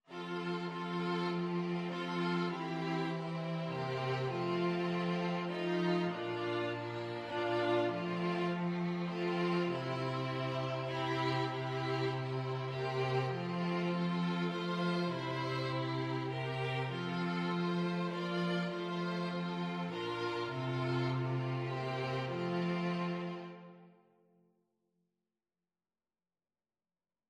3/4 (View more 3/4 Music)
String Quartet  (View more Easy String Quartet Music)
Classical (View more Classical String Quartet Music)